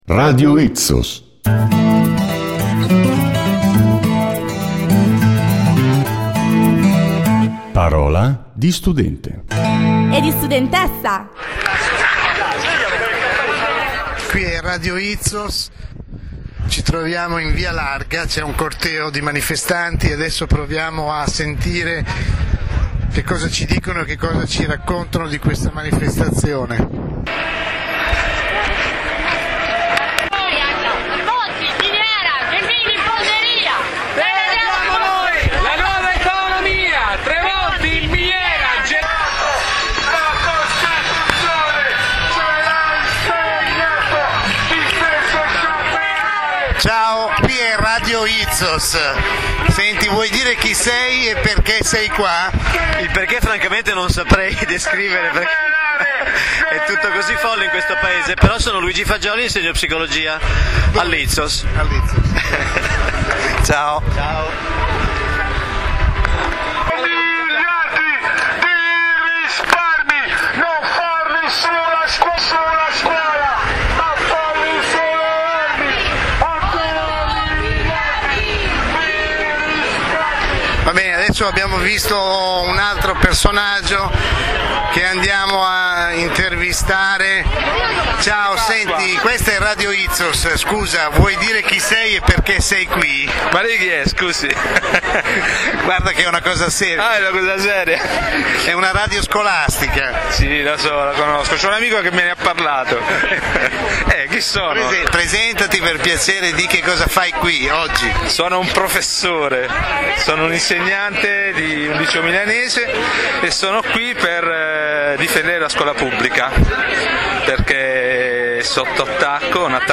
Download: Intervista di RadioItsos
manifestazione.mp3